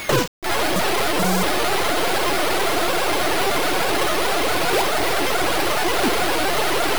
Sure, there's sound, and it's not great.
There's no music.
Here's a sound clip of me getting a base hit, them throwing the ball into the infield, and the "roaring crowd."